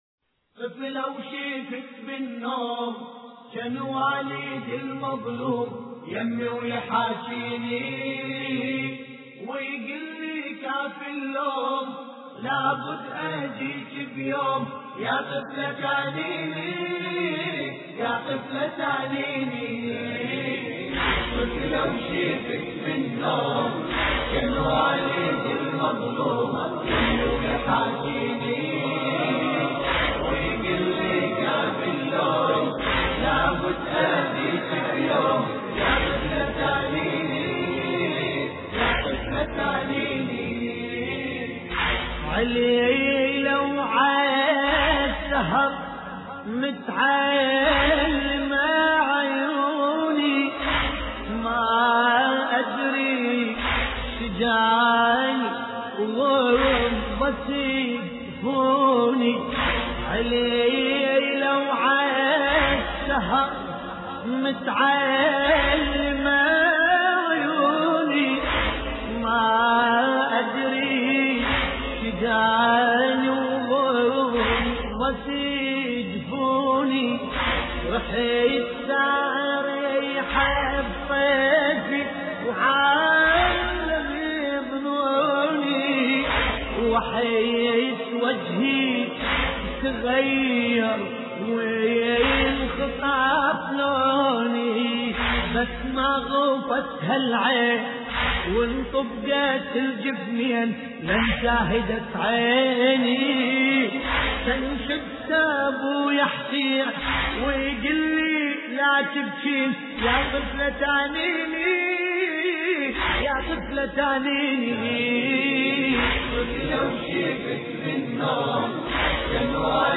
مراثي أهل البيت (ع)